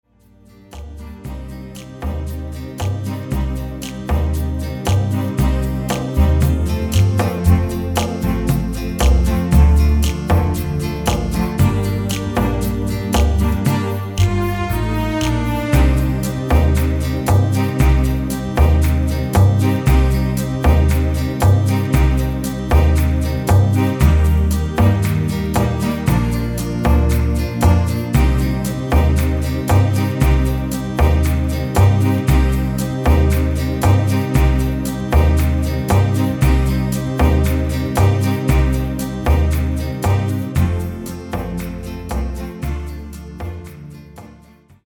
פלייבק איכותי – תואם מקור